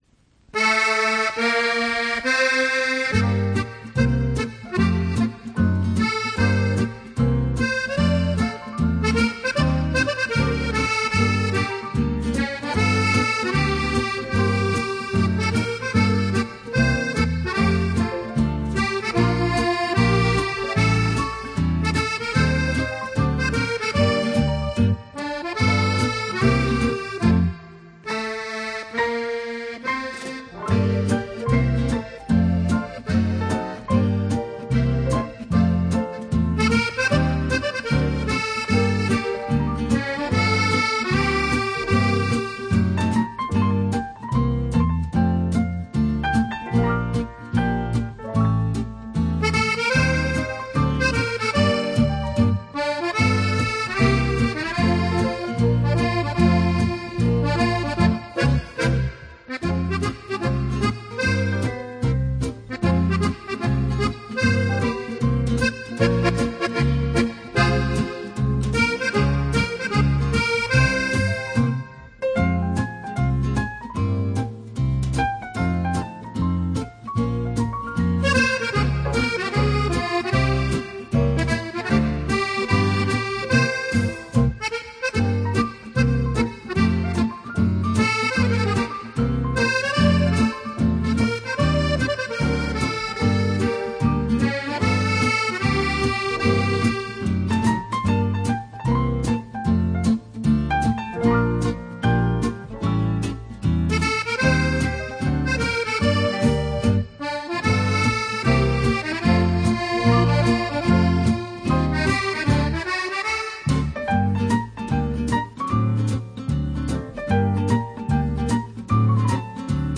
Жанр: Easy Listening, Accordeon